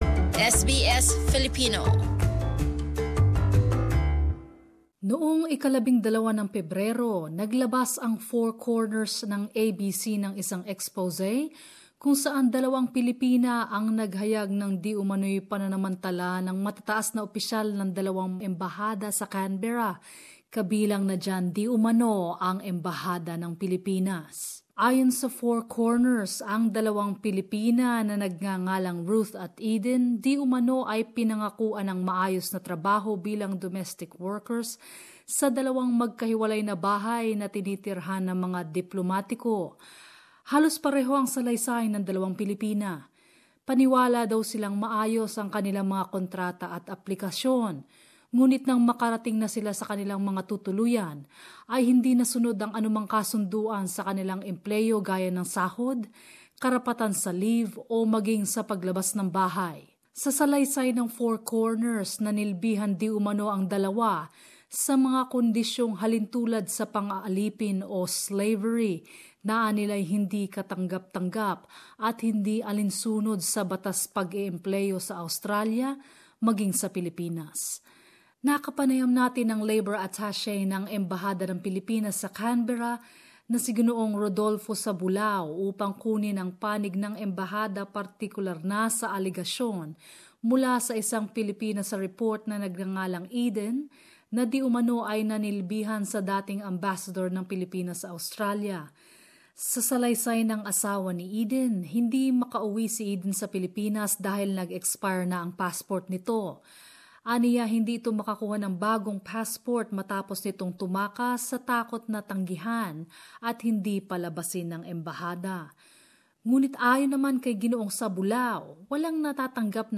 Special Report: Diumano'y pananamantala sa mga kasambahay sa mga tahanan ng mga Embahada sa Canberra